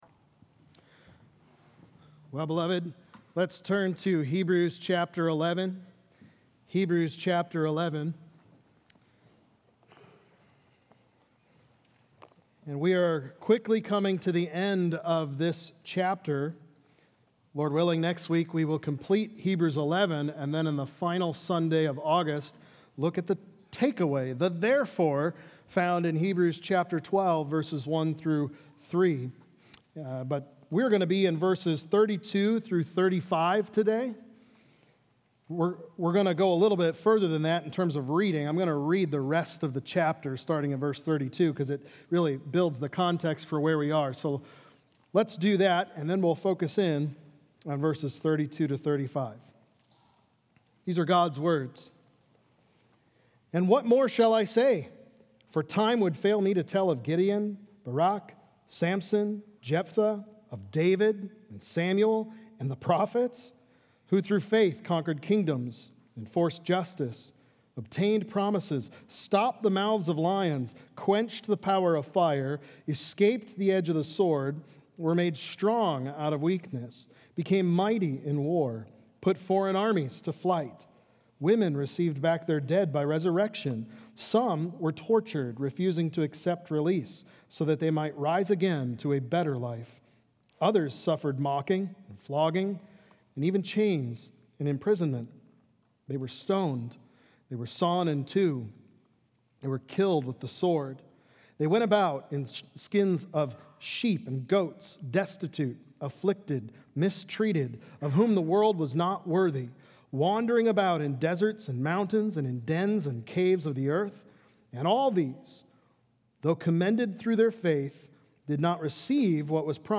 Faith’s Power, Glory, & Hope | Baptist Church in Jamestown, Ohio, dedicated to a spirit of unity, prayer, and spiritual growth